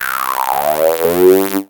前の音と次の音がスムーズに繋がって聴こえる機能です。
• 2. ポルタメントあり・・・
ノート・コラムを２列使って、前の音と次の音が少しずつ重なるように入力してください。それで音が繋がって聴こえます。